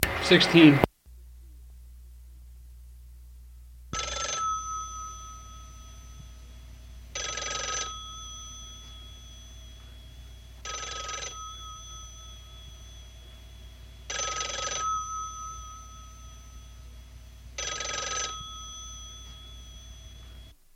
复古钟声和蜂鸣器 " G2031旋转电话
描述：旋转电话铃声。像铃铛一样低调的低音铃铛采用厚塑料制成，没有任何混响。 这些是20世纪30年代和20世纪30年代原始硝酸盐光学好莱坞声音效果的高质量副本。 40年代，在20世纪70年代早期转移到全轨磁带。我已将它们数字化以便保存，但它们尚未恢复并且有一些噪音。
标签： 电话 戒指 眼镜 经典
声道立体声